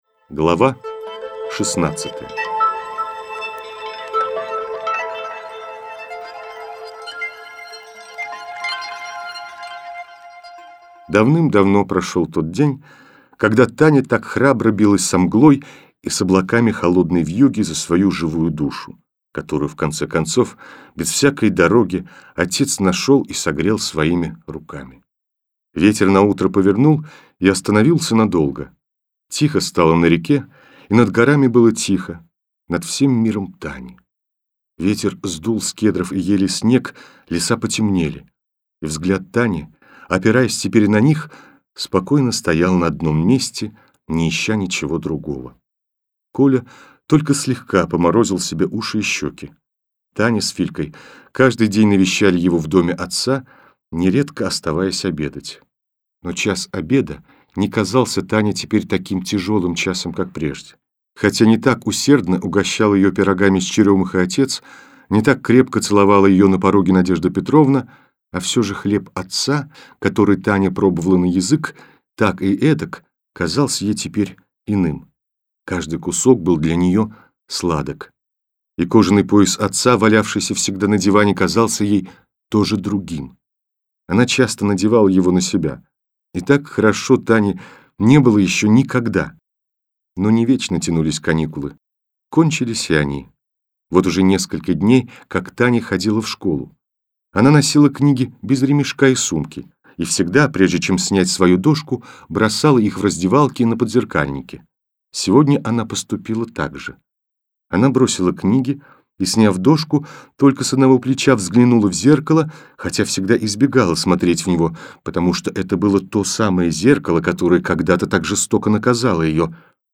Аудио книга Рувима Фраермана Дикая собака Динго или повесть о первой любви